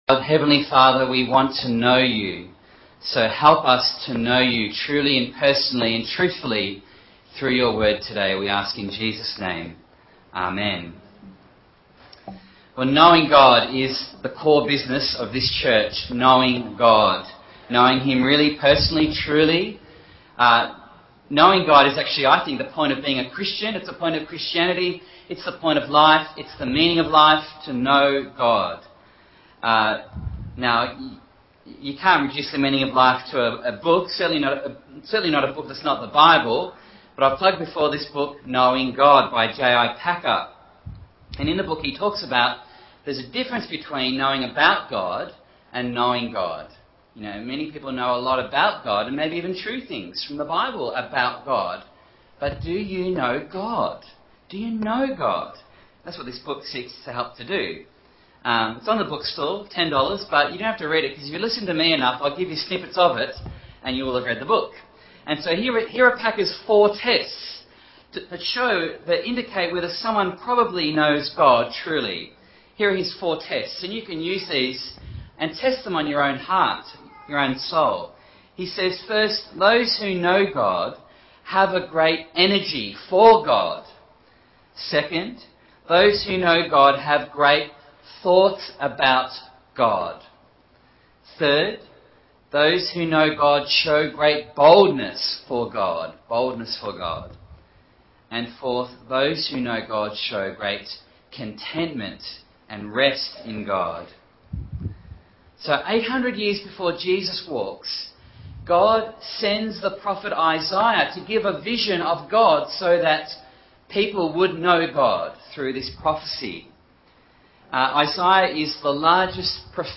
Bible Text: Isaiah 1:1-20 | Preacher